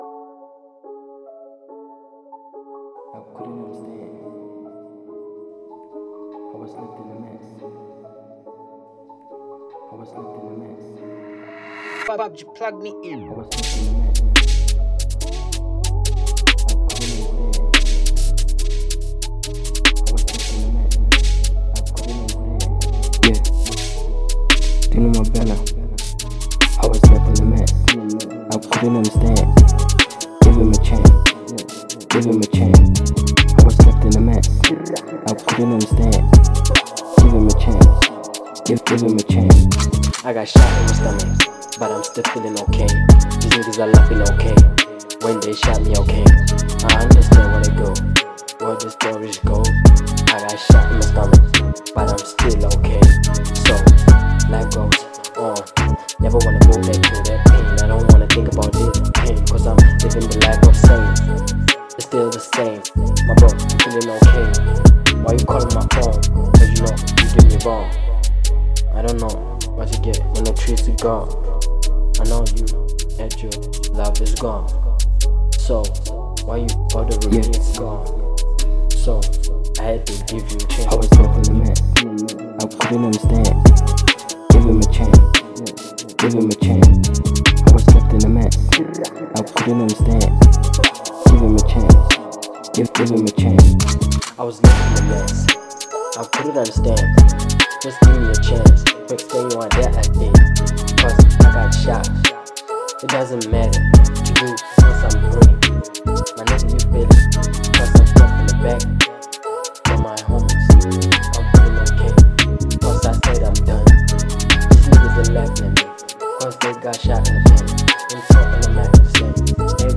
02:28 Genre : Trap Size